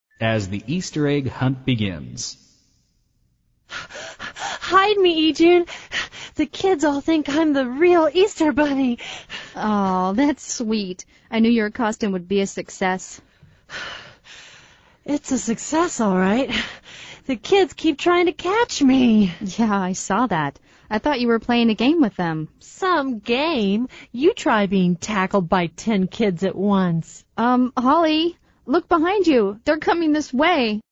美语会话实录第160期(MP3+文本):Easter egg